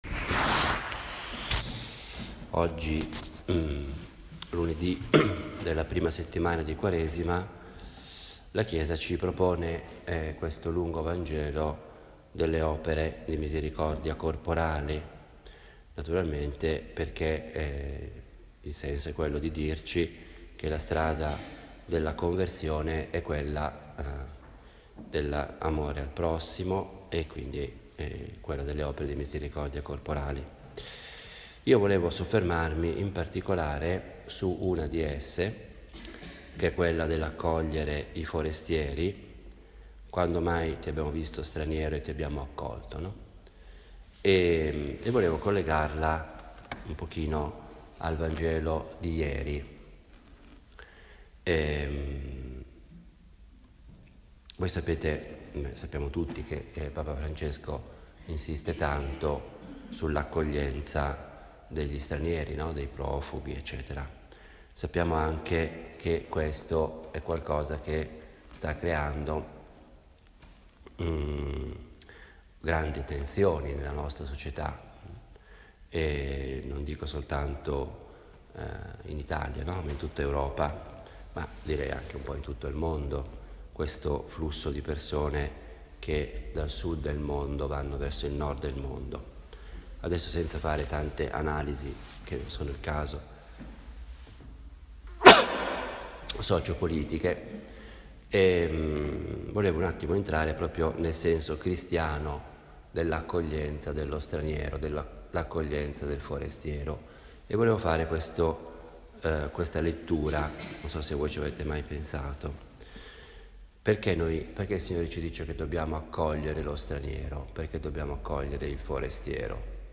La meditazione di questa settimana è più breve perché è l’omelia che ho pronunciato ieri al monastero di sant’Agnese, a Perugia.
Ha il carattere piano, proprio di una conversazione familiare e io la intendo come il mio dialogo personale – fatto ad alta voce – con Dio, la Madonna, ecc.
Le meditazioni che si trovano sul blog sono semplici registrazioni – senza nessuna pretesa particolare – di quelle che faccio abitualmente.